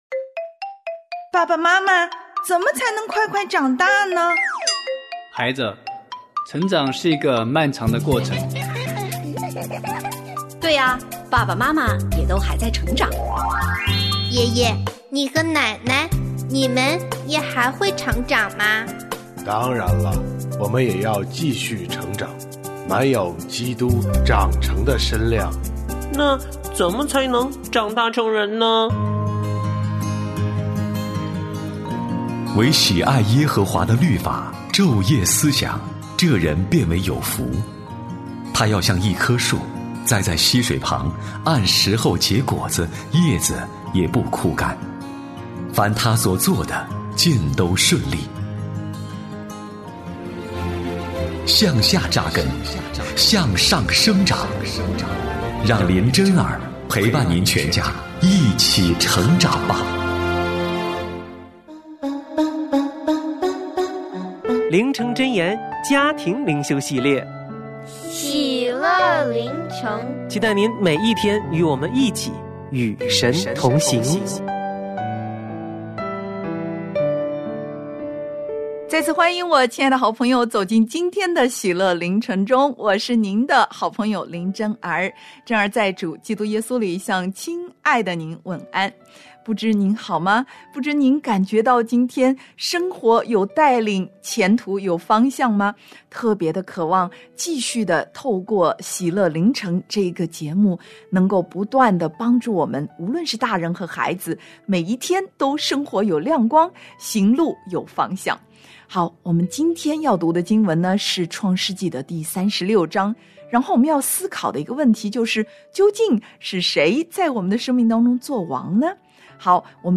我家剧场：圣经广播剧（149）书念妇人失而复得她的产业；亚兰王哈达生病了